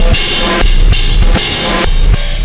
Black Hole Beat 13.wav